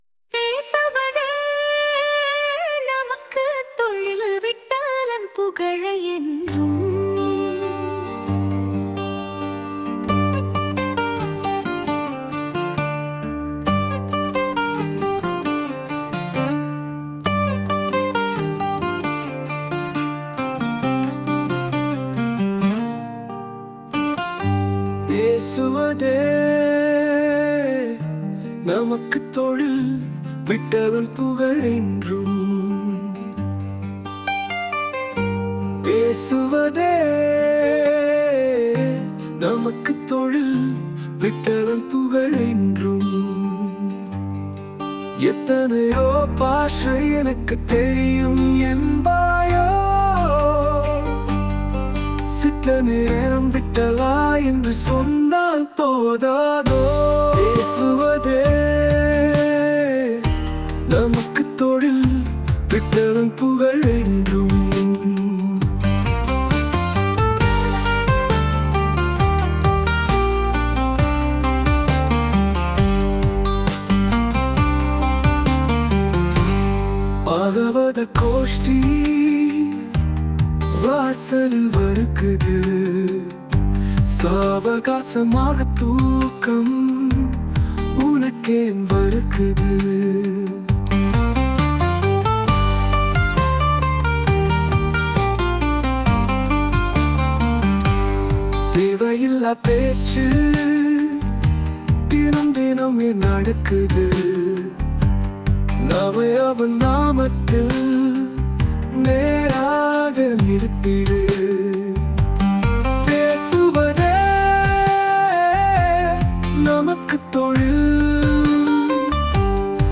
Posted in With AI Audio, தமிழ் அபங்கங்கள்
செயற்கை அறிவுத்திறன் கொண்டு இசையமைத்து பாட வைத்தவர்
Tamil-Abhangam-Vittalan.mp3